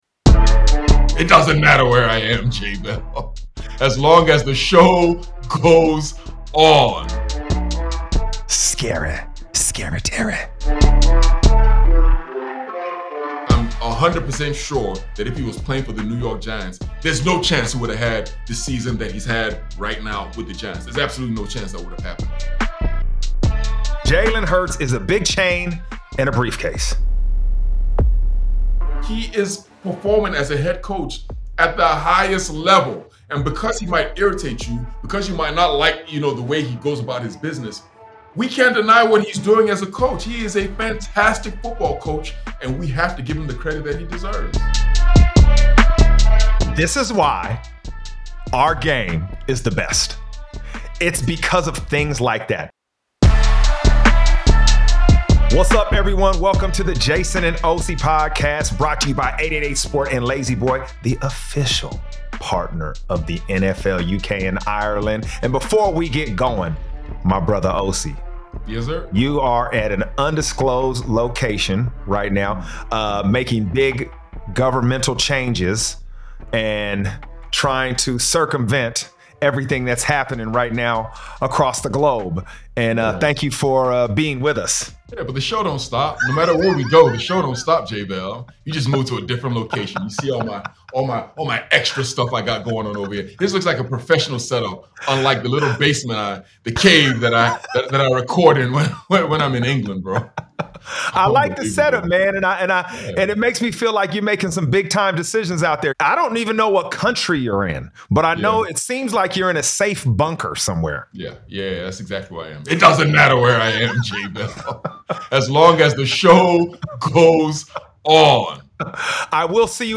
Jason Bell and two-time Super Bowl champ Osi Umenyiora take you through the biggest stories in the NFL. No script.
Just two former players taking you into the locker room and inside the game.